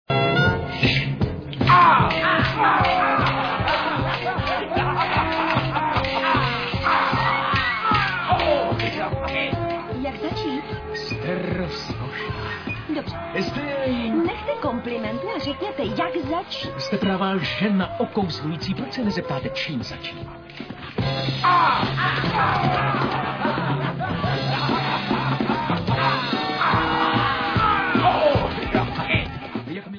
• zvuk: Stereo
sledovat novinky v oddělení Mluvené slovo